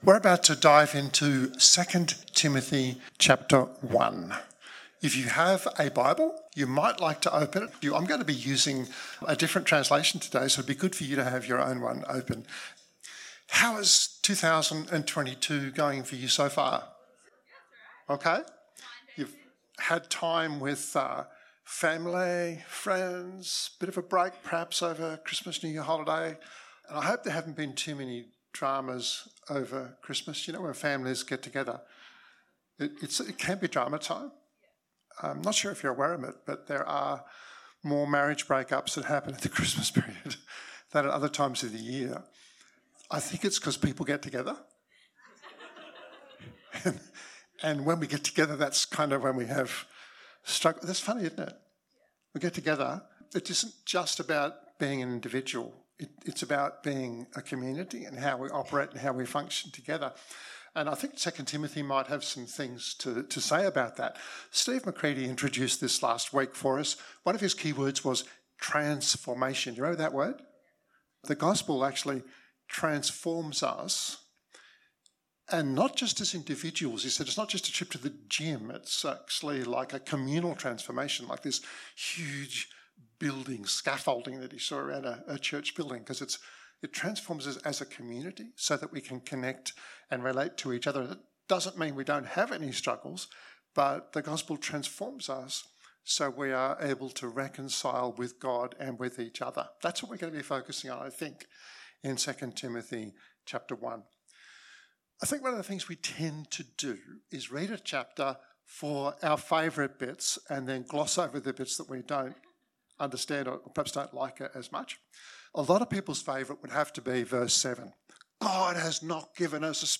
This 35 minute podcast was recorded at Riverview Joondalup 2022-01-09.